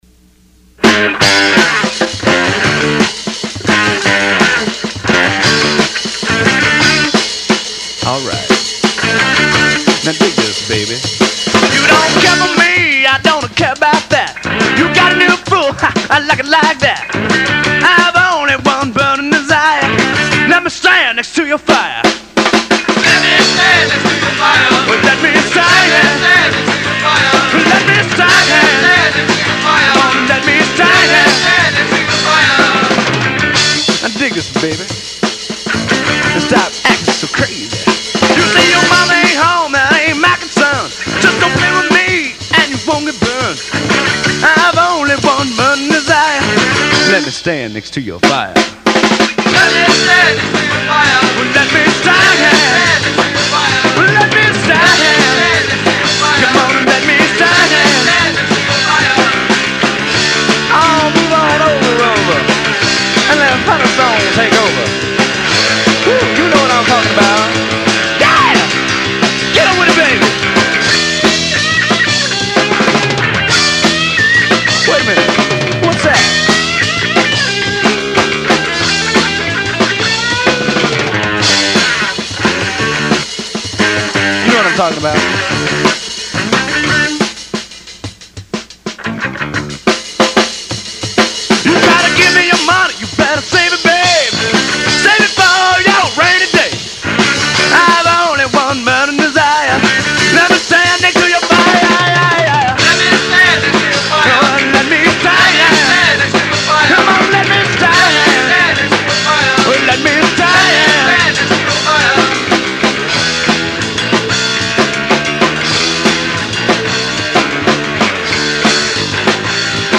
Recorded at Zombie Birdhouse, Oxford, MS
Bass
Drums
Electric Guitar
Vocals